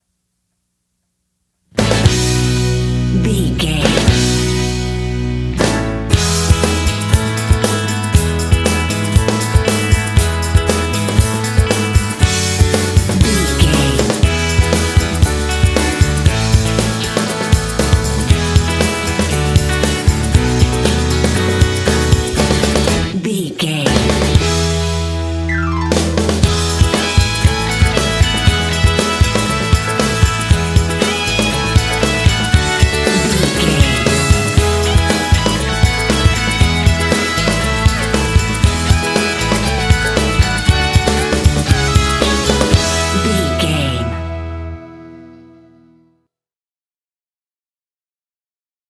Uplifting
Ionian/Major
bouncy
groovy
bright
drums
piano
percussion
electric guitar
bass guitar
rock
pop
alternative rock
indie